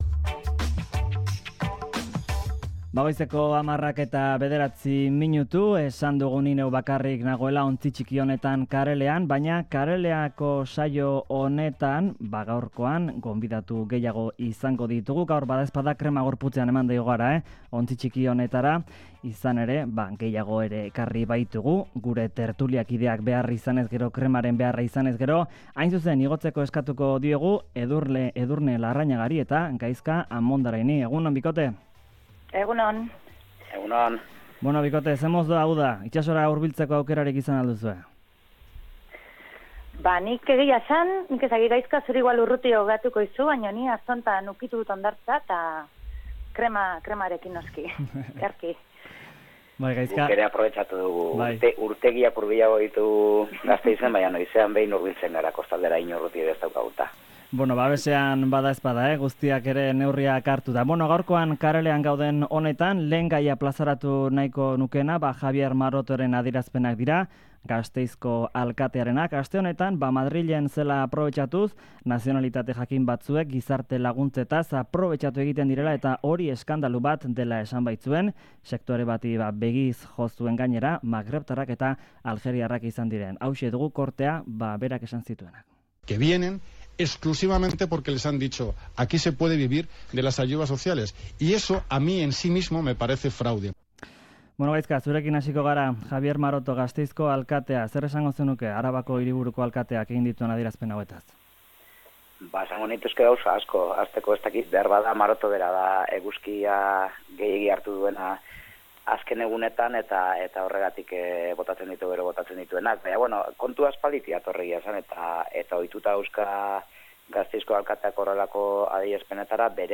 Tertulia Karelean saioan